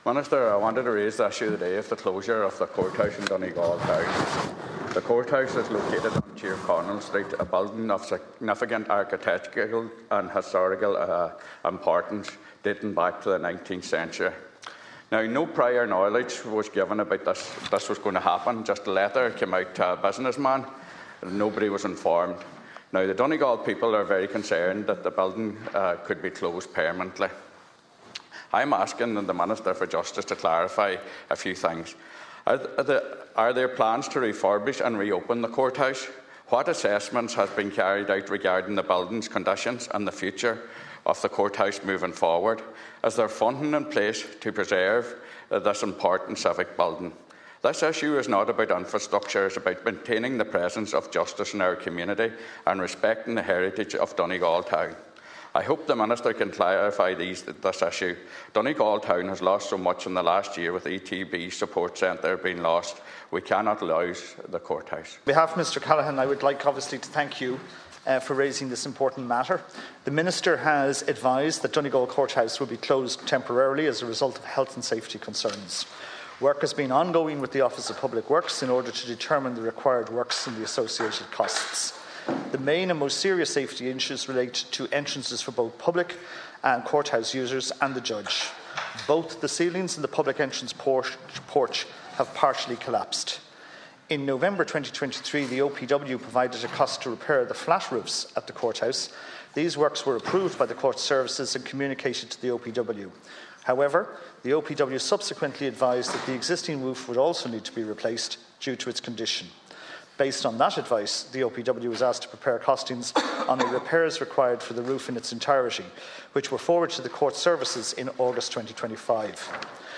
Future of Donegal Town Courthouse raised in Seanad
The issue was raised by Cllr Manus Boyle, who stressed the importance of keeping court sittings in the town.